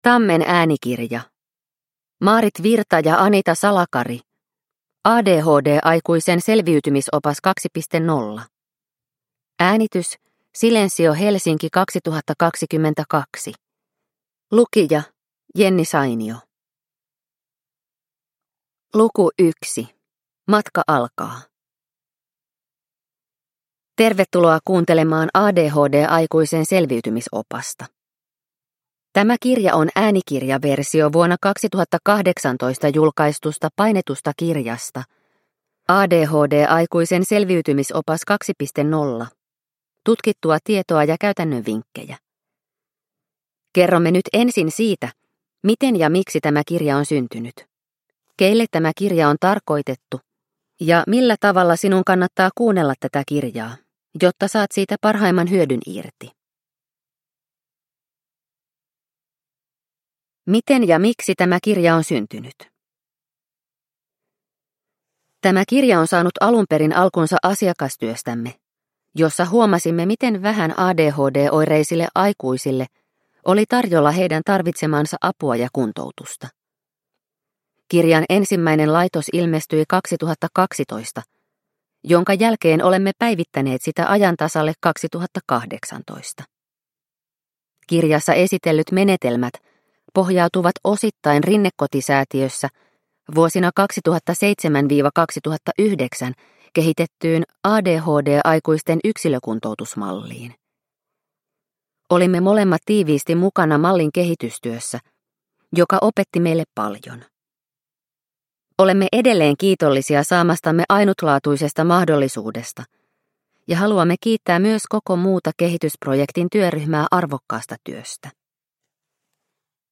ADHD-aikuisen selviytymisopas 2.0 – Ljudbok – Laddas ner